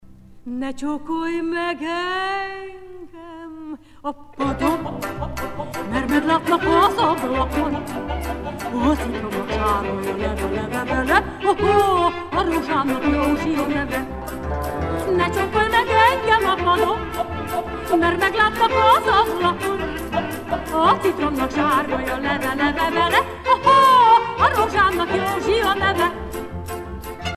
Székely folk songs
Pièce musicale éditée